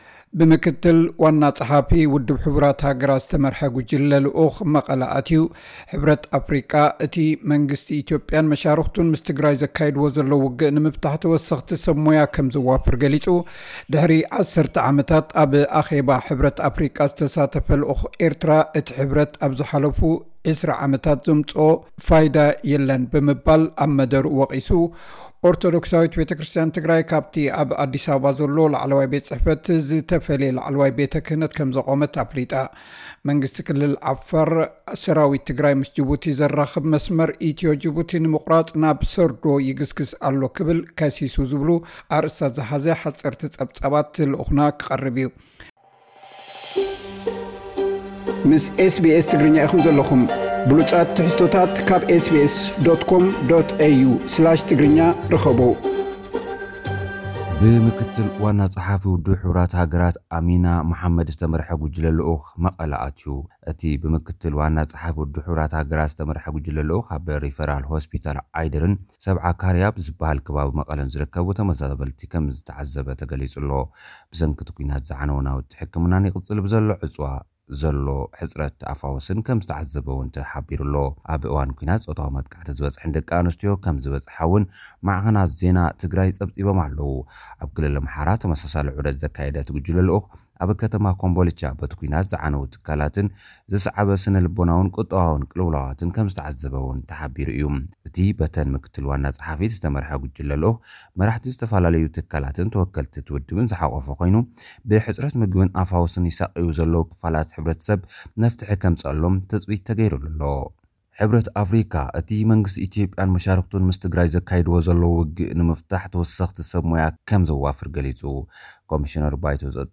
ዝብሉ ኣርእስታት ዝሓዘ ሓጸርቲ ጸብጻባት ልኡኽና ክቐርብዩ።